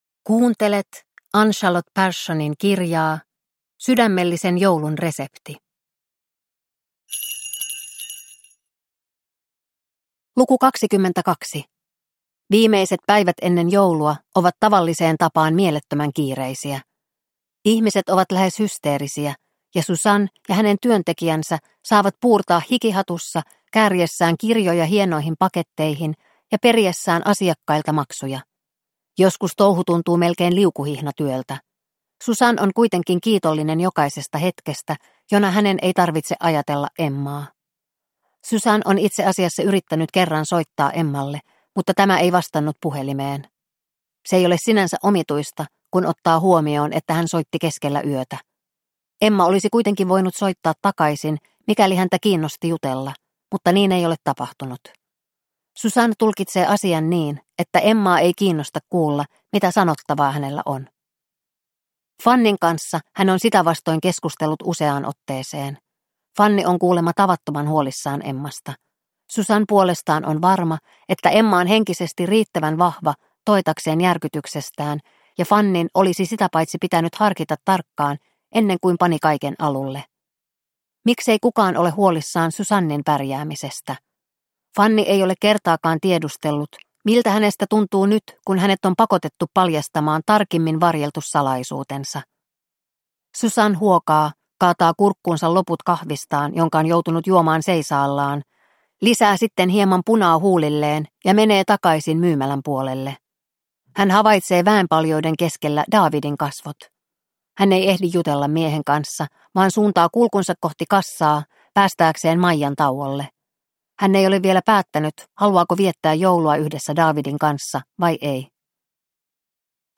Sydämellisen joulun resepti - Luukku 22 – Ljudbok – Laddas ner